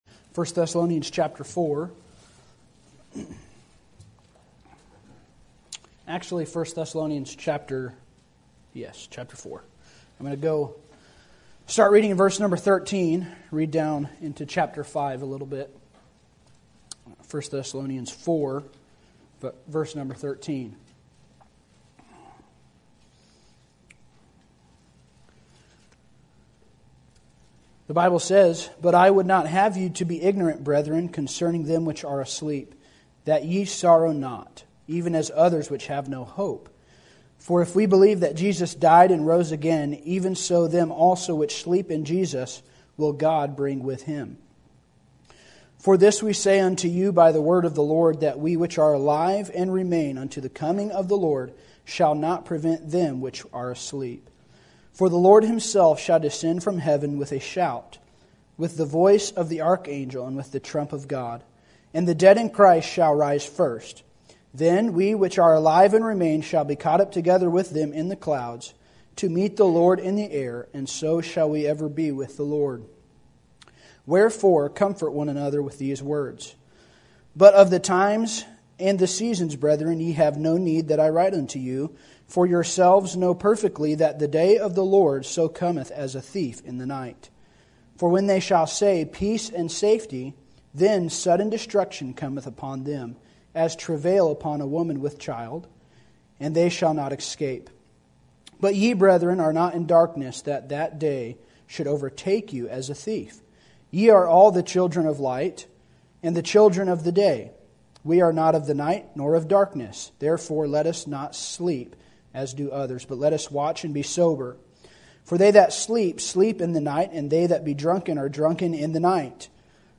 Sermon Topic: General Sermon Type: Service Sermon Audio: Sermon download: Download (19.39 MB) Sermon Tags: 1 Thessalonians Rapture Salvation Doctrine